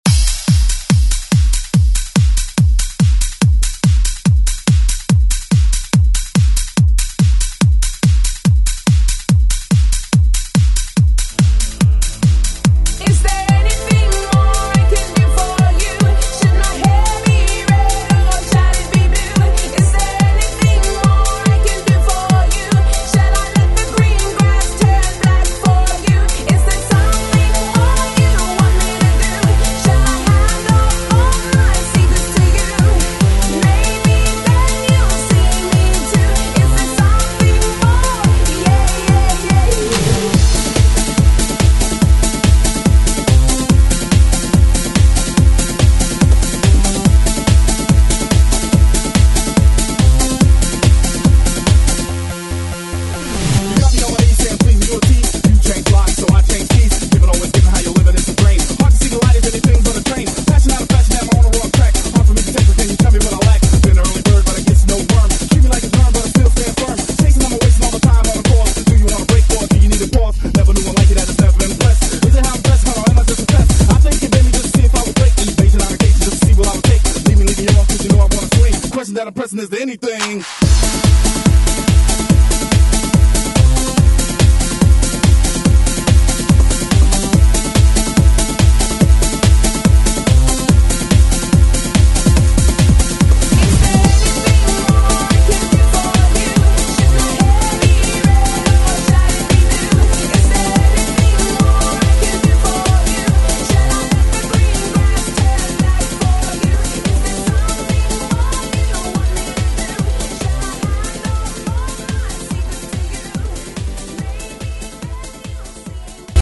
BPM: 127